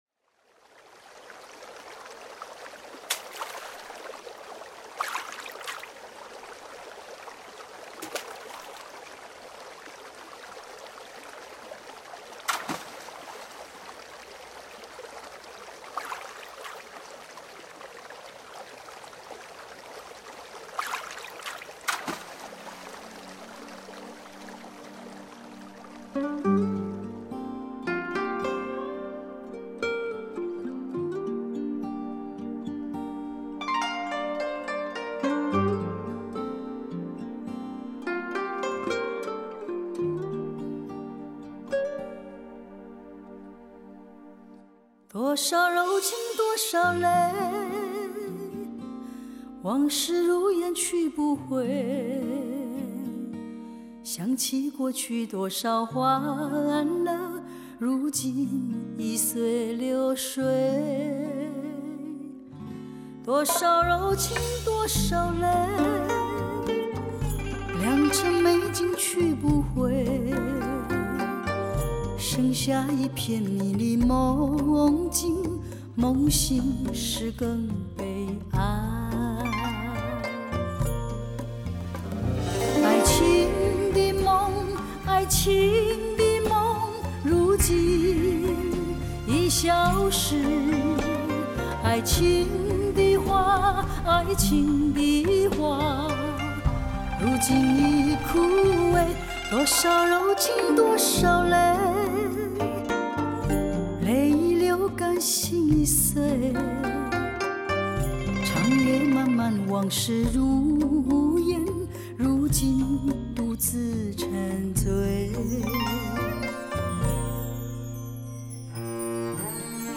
创造超乎想象完美6.1环绕声震撼体验!